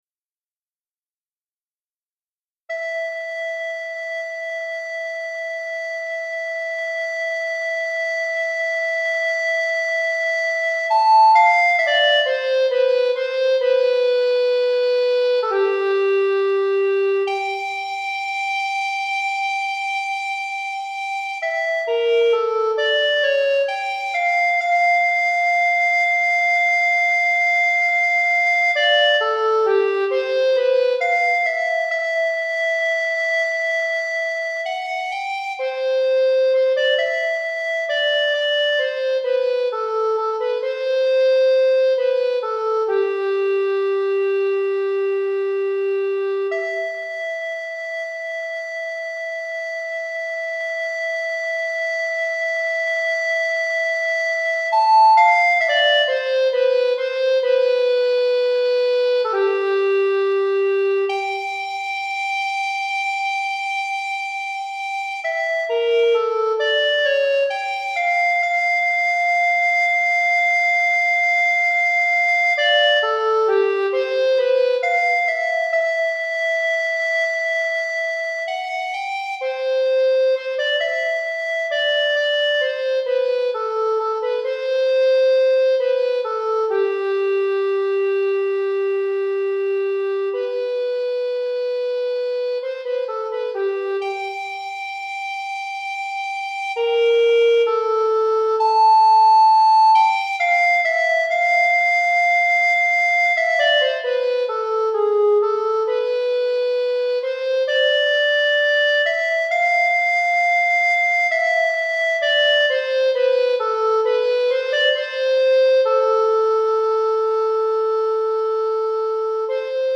Flûte à Bec Solo